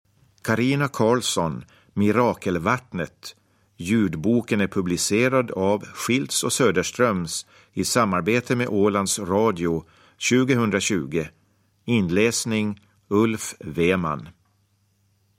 Mirakelvattnet – Ljudbok – Laddas ner